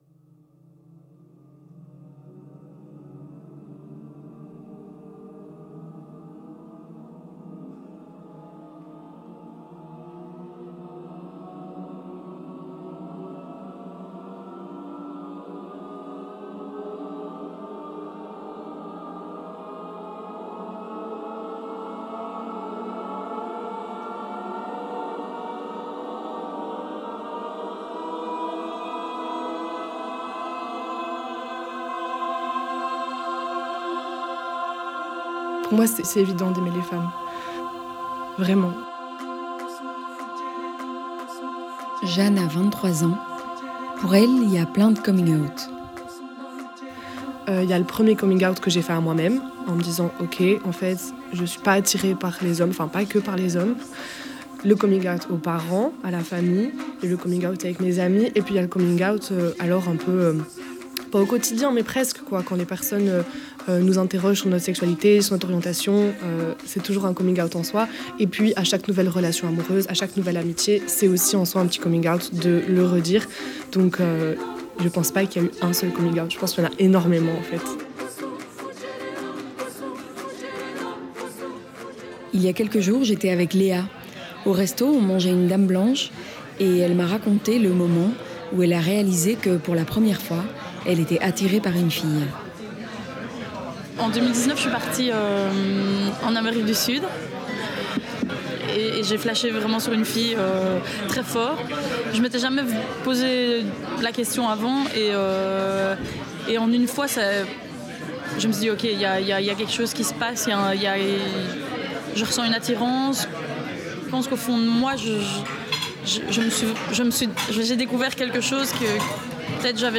Accueil > Documentaires > " Y a rien d’plus beau qu’le corps d’une femme "